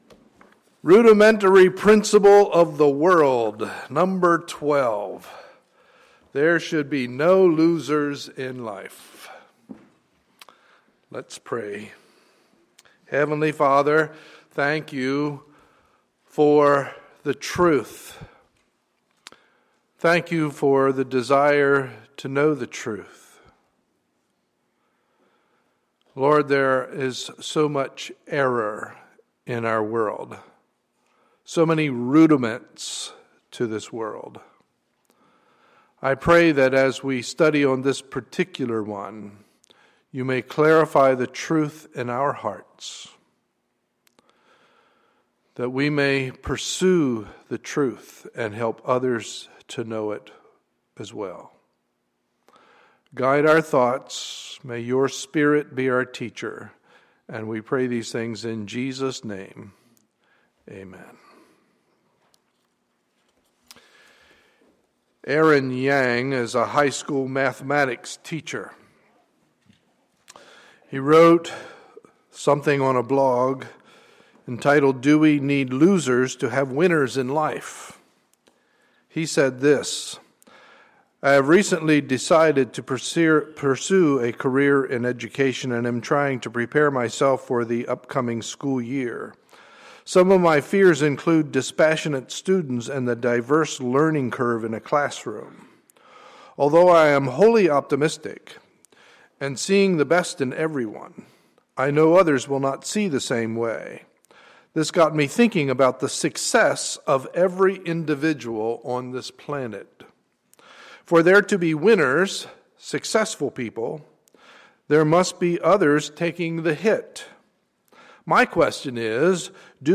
Sunday, June 1, 2014 – Morning Service